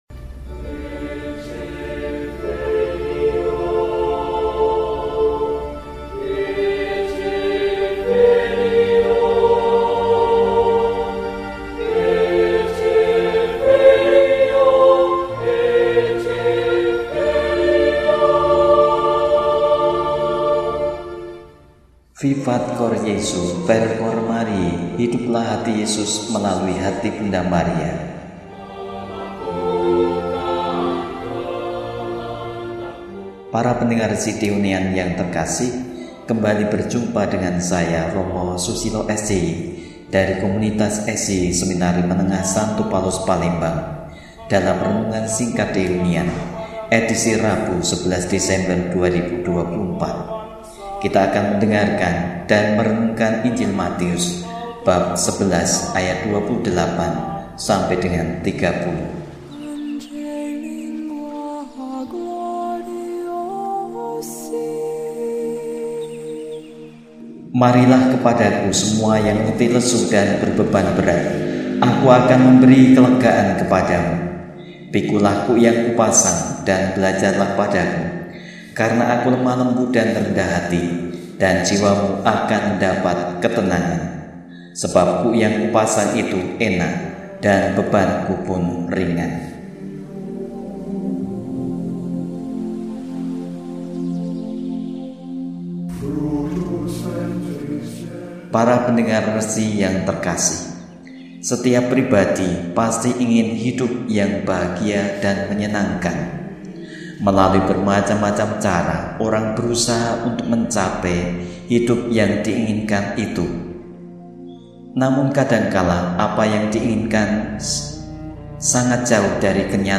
Rabu, 11 Desember 2024 – Hari Biasa Pekan II Adven – RESI (Renungan Singkat) DEHONIAN